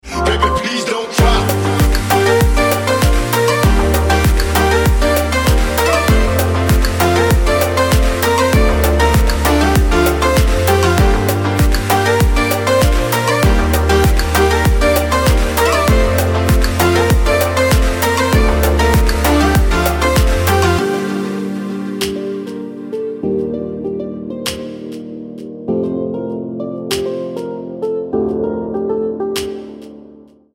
• Качество: 320, Stereo
dance
спокойные
club